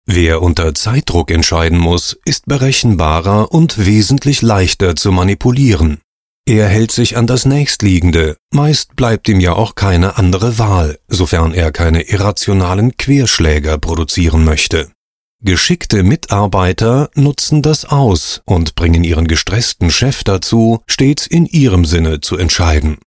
Sprechprobe: eLearning (Muttersprache):
Personalmanagement - E-Learning, sachlich, klar.mp3